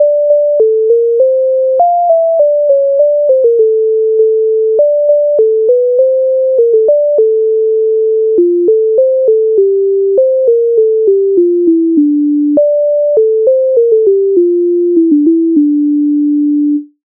MIDI файл завантажено в тональності d-moll
Українська народна пісня